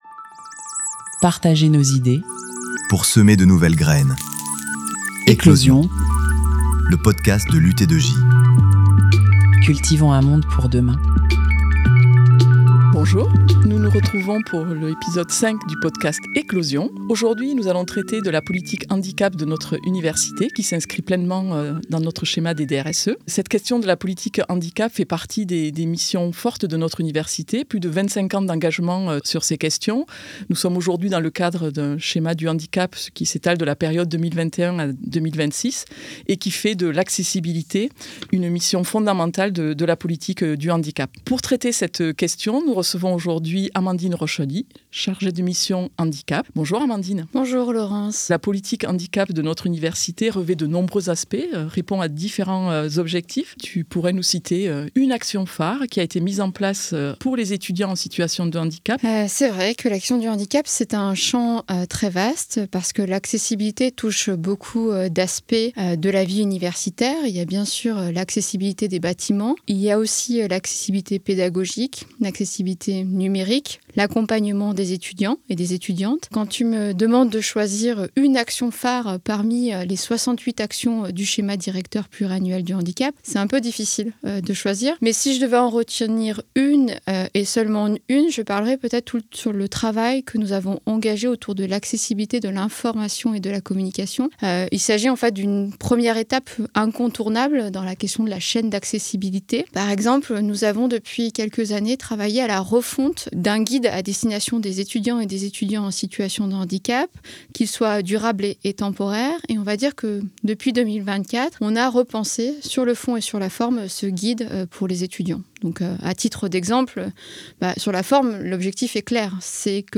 Elle reçoit chaque mois un ou une invitée différente qui met en lumière un axe du schéma illustré par une action concrète.